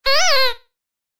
grito2.wav